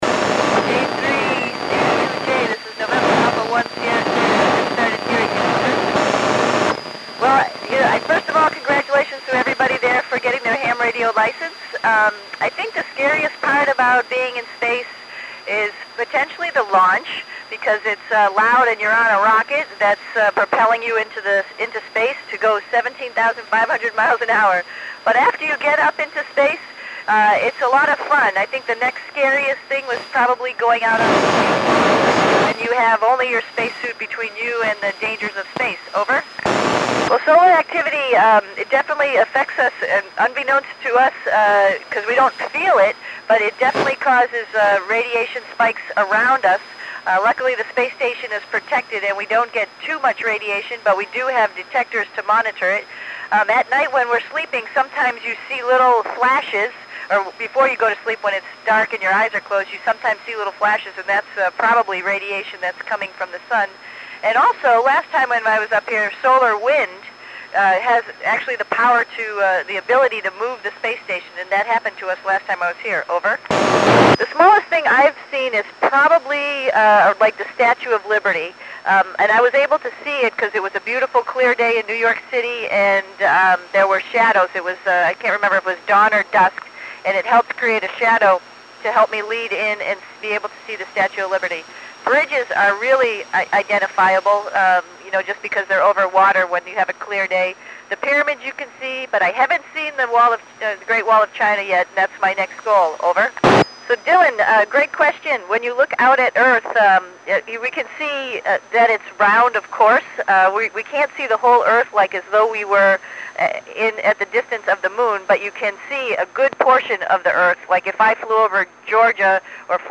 Capt. Sunita Williams (NA1SS) speaks to youth at the National Electronics Museum in Linthicum, MD, U.S.A. at 1505 UTC on 01 September 2012 via telebridge station K3CUJ.